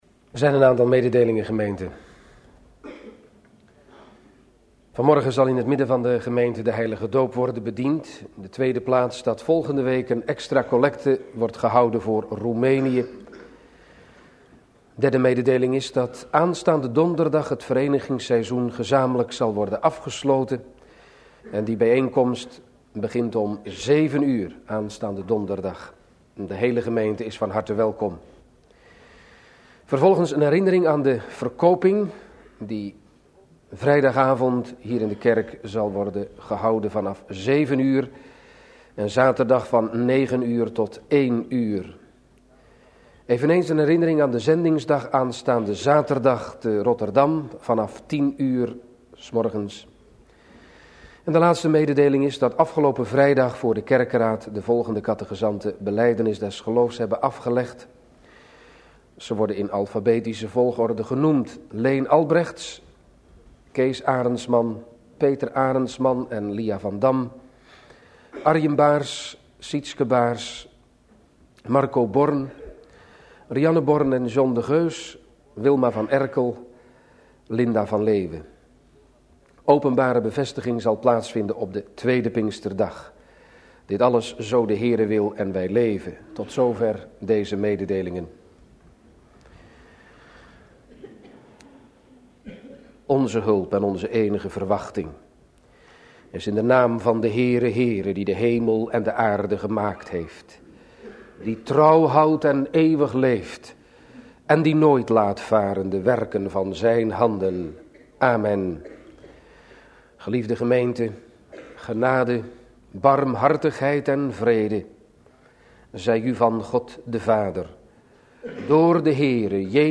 Het archief bevat 10644 preken vanaf 1953 van ruim 200 predikanten uit vooral de Chr. Ger. Kerken.